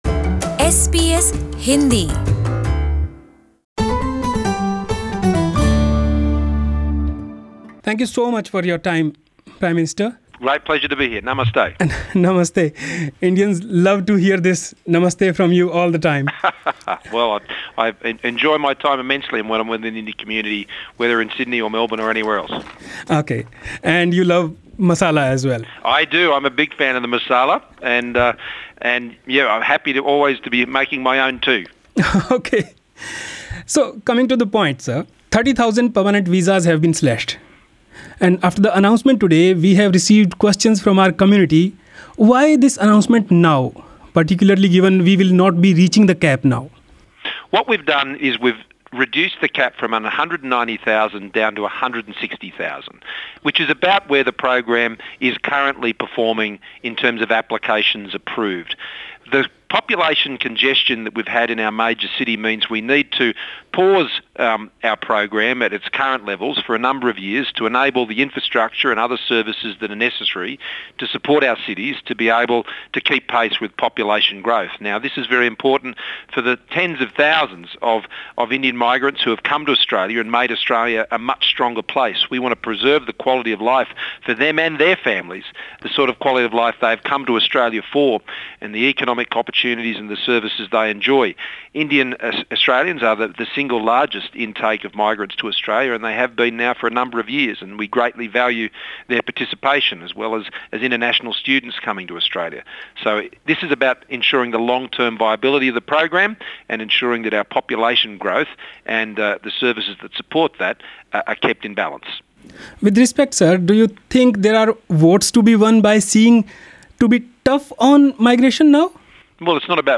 EXCLUSIVE: Prime Minister Scott Morrison tells SBS Hindi that he believes regional areas of Australia are ready to welcome a large number of migrants.